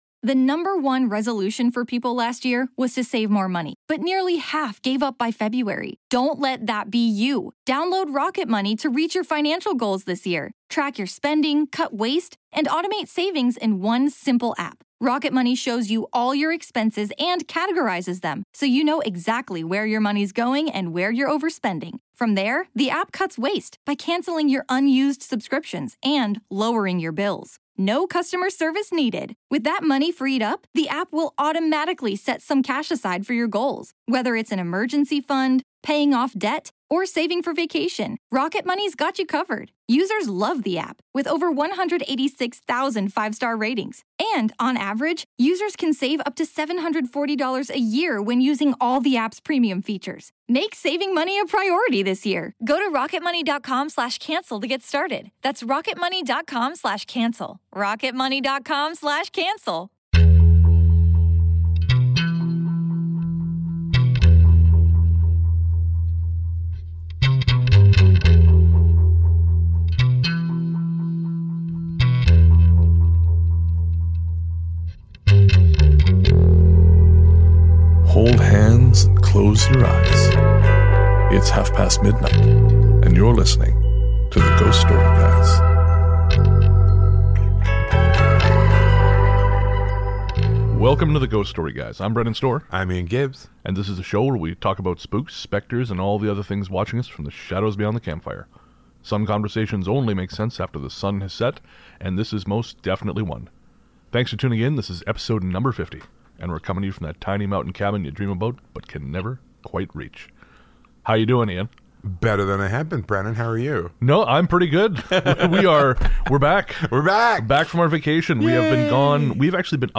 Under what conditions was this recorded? There's a little bit of audio unpleasantness around the 16m mark. Our apologies - it was a technical glitch I wasn't able to resolve before release time.